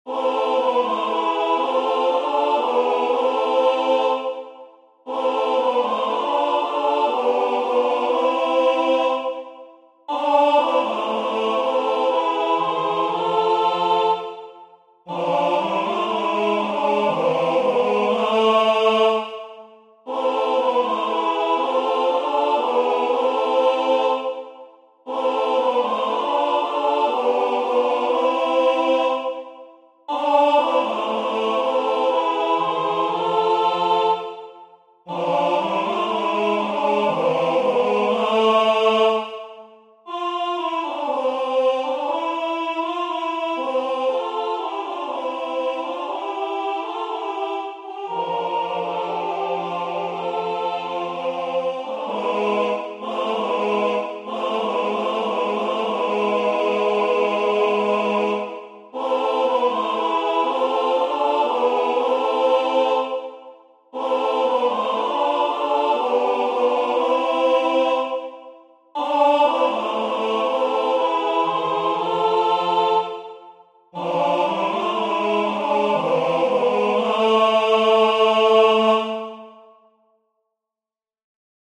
Full Soprano Alto Tenor Bass